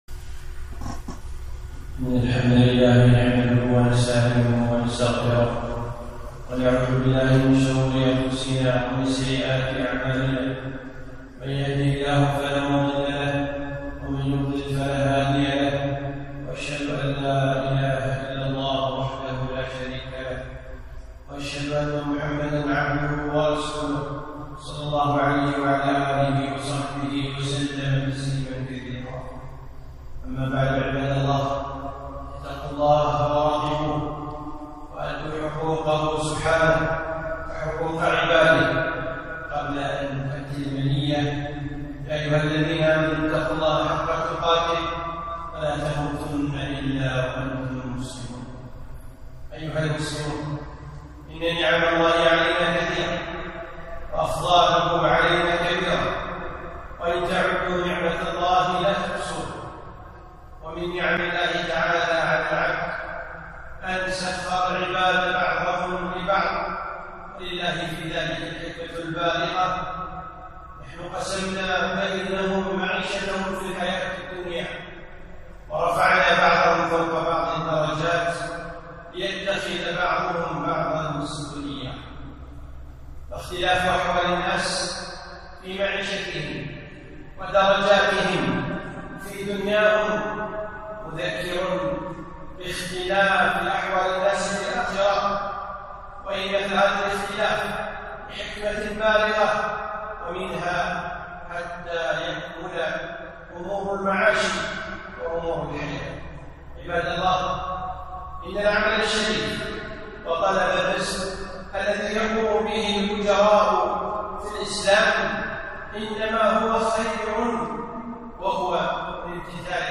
خطبة - حقوق الأجراء